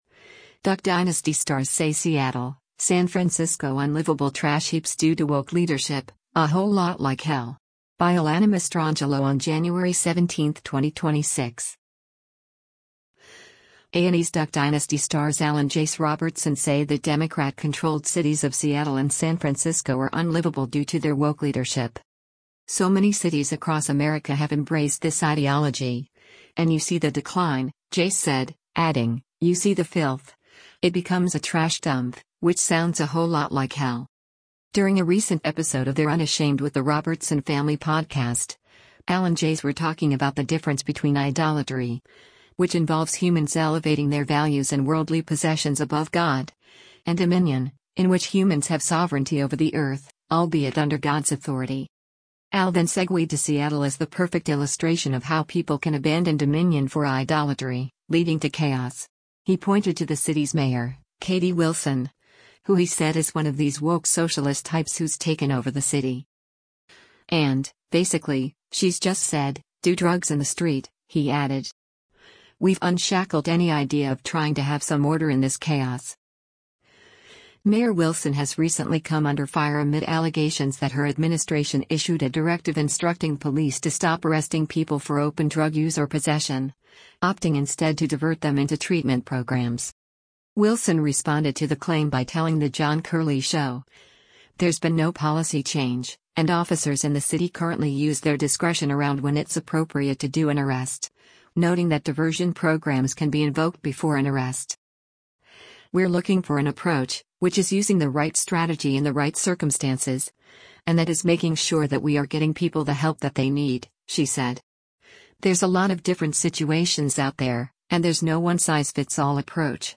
During a recent episode of their Unashamed with the Robertson Family podcast, Al and Jase were talking about the difference between “idolatry,” which involves humans elevating their values and worldly possessions above God, and “dominion,” in which humans have sovereignty over the earth — albeit under God’s authority.